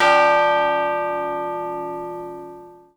I used a gong sound for the hours and Big Ben chimes at the very end.
001 gong.wav